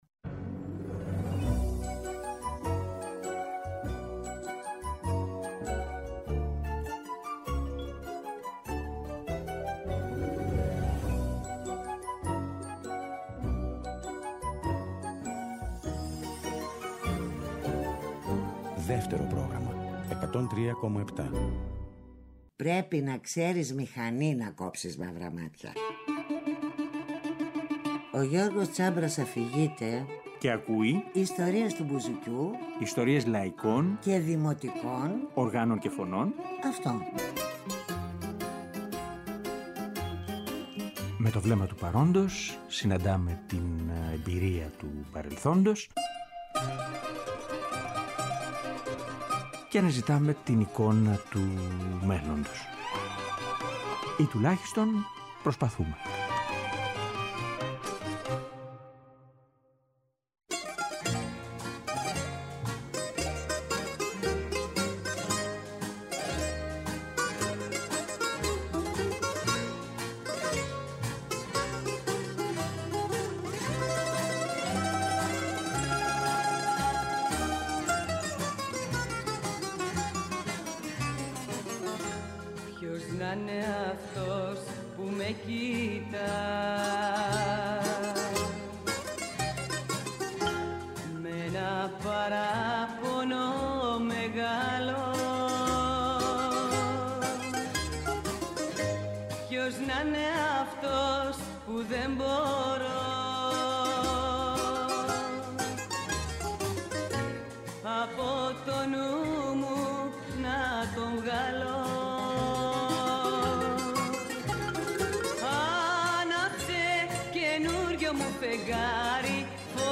Και παραμένει μέχρι σήμερα στα ακούσματά μας, κορυφαία περίπτωση τραγουδίστριας με τέτοια δυναμική στην έκφραση.